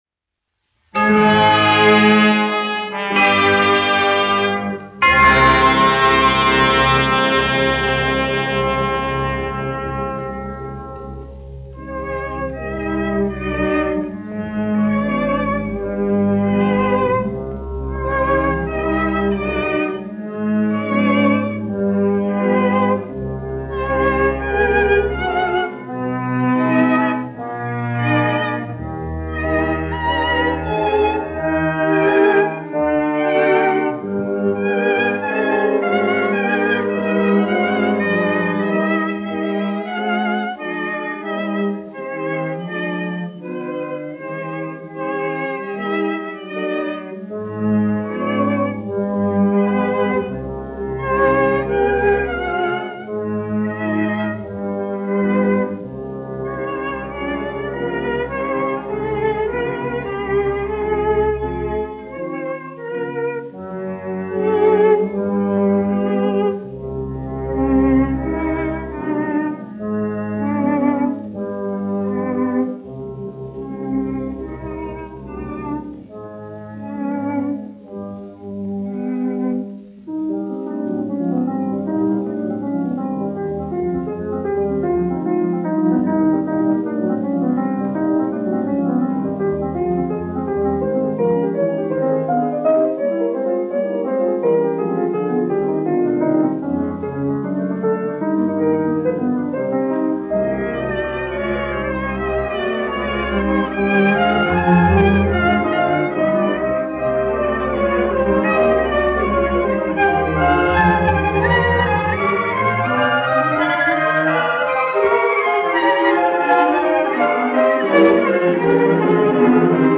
1 skpl. : analogs, 78 apgr/min, mono ; 25 cm
Orķestra mūzika, aranžējumi
Skaņuplate
Latvijas vēsturiskie šellaka skaņuplašu ieraksti (Kolekcija)